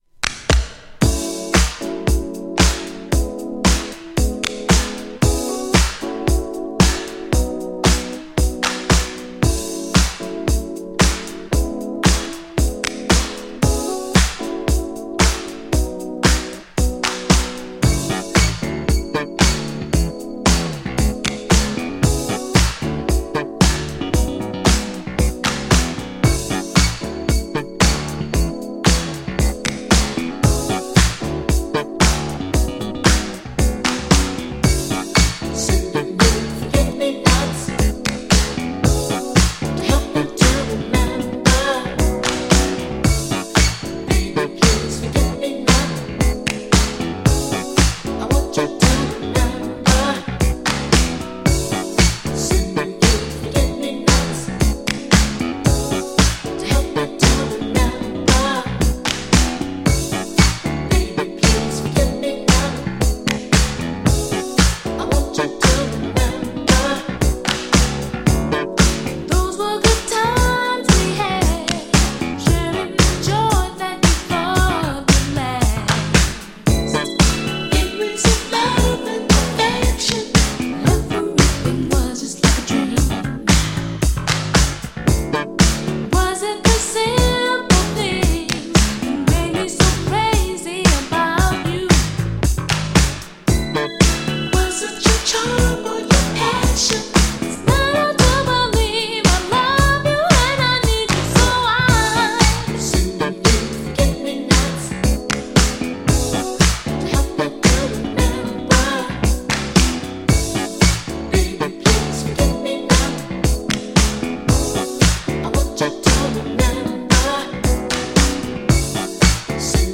GENRE Dance Classic
BPM 91〜95BPM